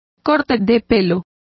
Complete with pronunciation of the translation of haircut.